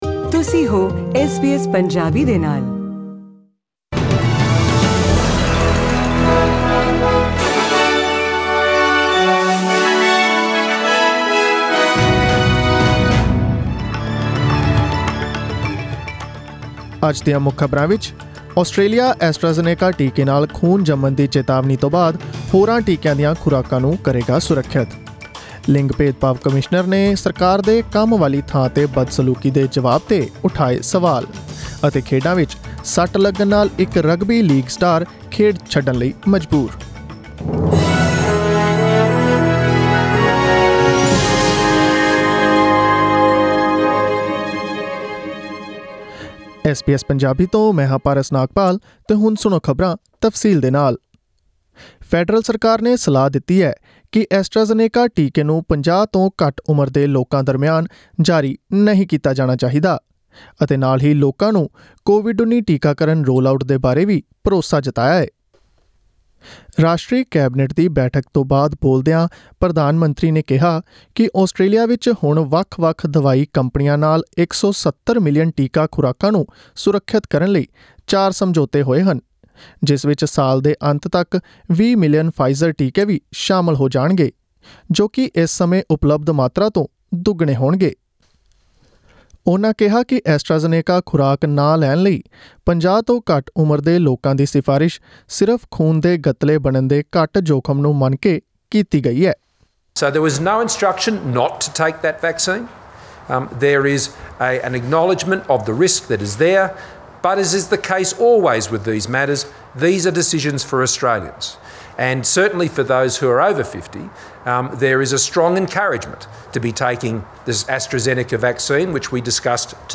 Click on the audio icon in the picture above to listen to the news bulletin in Punjabi.